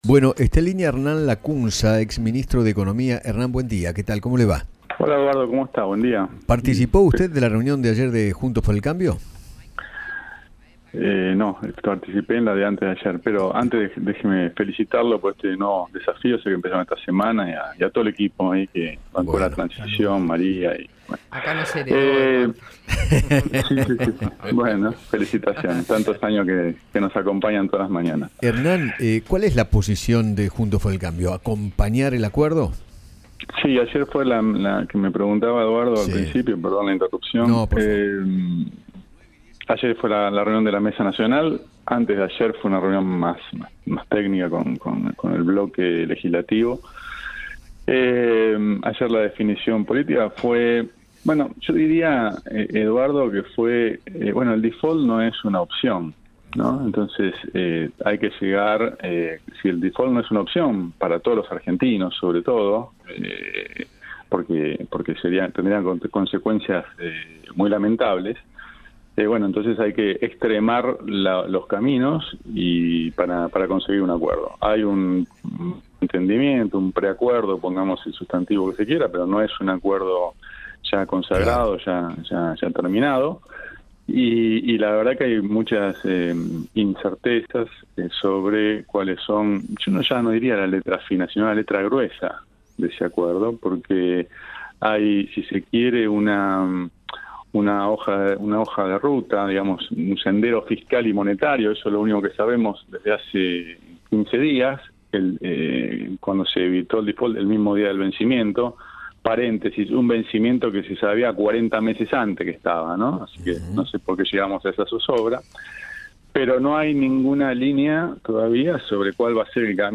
Hernán Lacunza, exministro de Hacienda de la Nación, conversó con Eduardo Feinmann sobre el apoyo de Juntos por el Cambio al acuerdo para pagarle al FMI, con la condición de que no suban los impuestos. Además, habló sobre la segmentación a los subsidios energéticos que propuso el Gobierno.